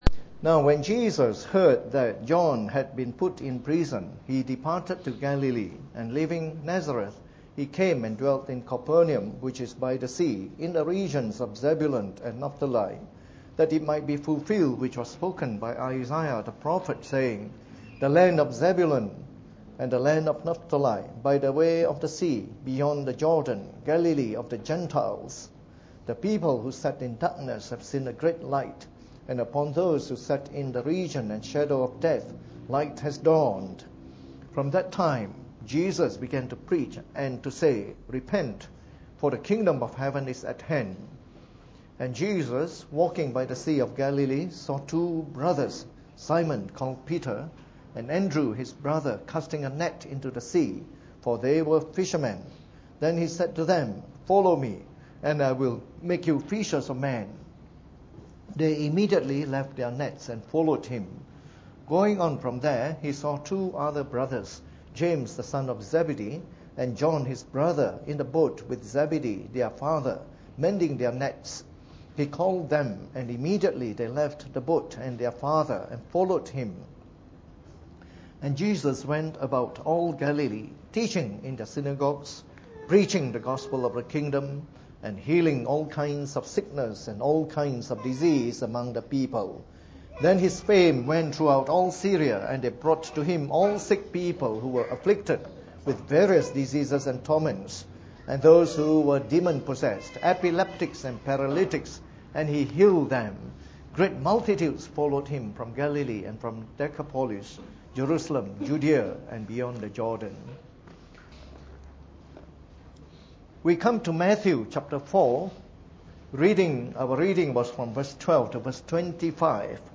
Preached on the 3rd of February 2016 during the Bible Study.